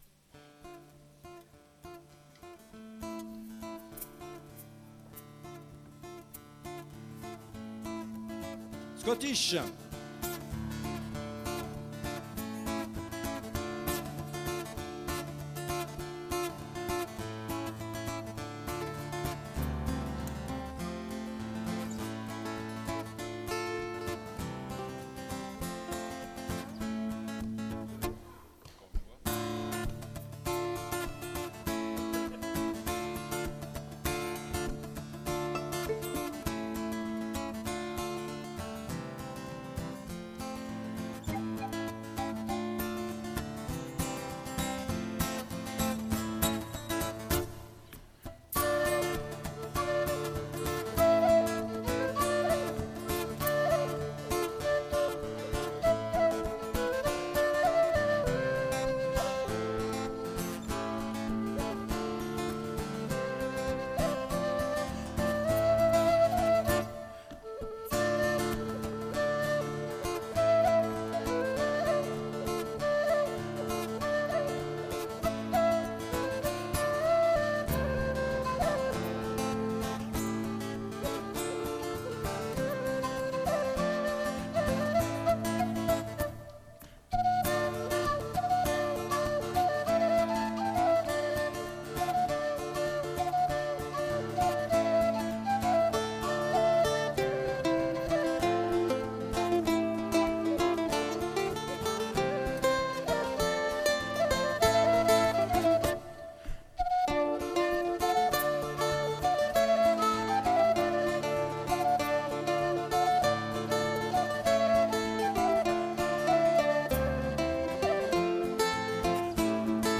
Die Fistoulig spielen europäische Tanzmusik mit einer Vorliebe für keltische Musik.
ou au bal  de l'ONG Partage Tanzanie à Vendenheim en 2018